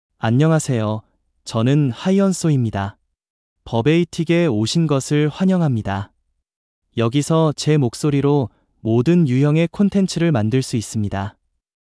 HyunsuMale Korean AI voice
Hyunsu is a male AI voice for Korean (Korea).
Voice sample
Hyunsu delivers clear pronunciation with authentic Korea Korean intonation, making your content sound professionally produced.